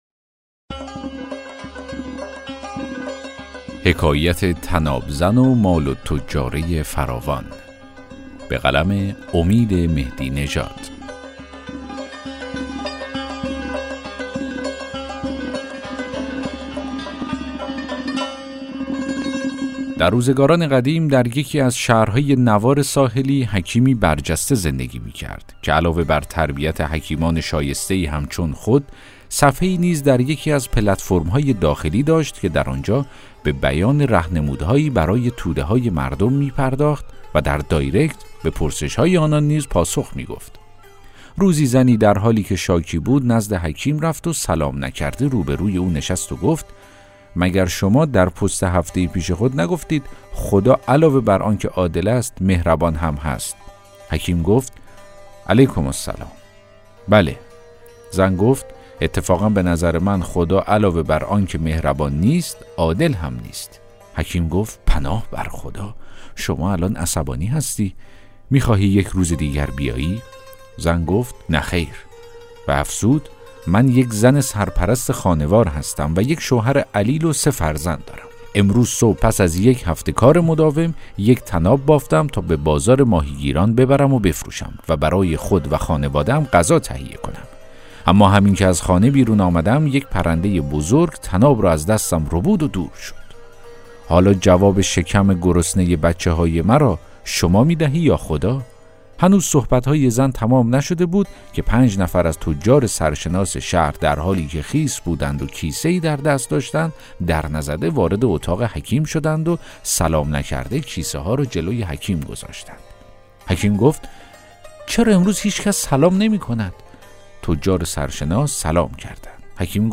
داستان صوتی: حکایت طناب زن و مال التجاره فراوان